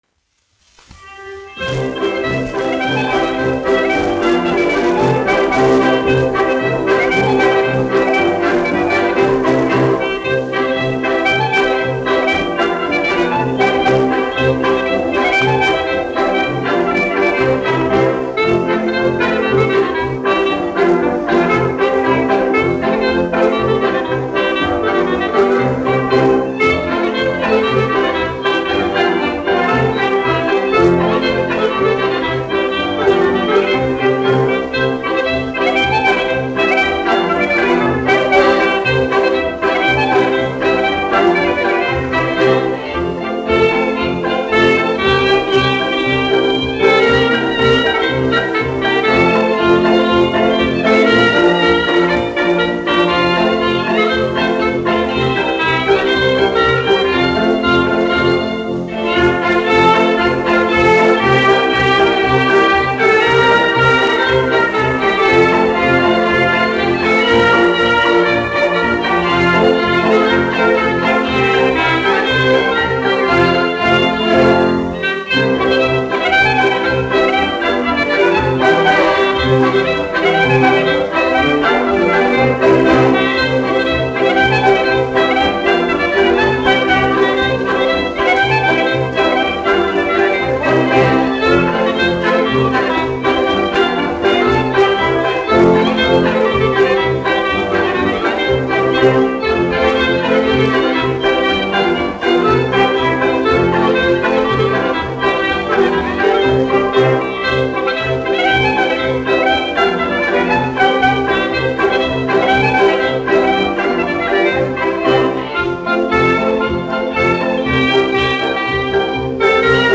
1 skpl. : analogs, 78 apgr/min, mono ; 25 cm
Polkas
Tautas deju mūzika -- Latvija
Skaņuplate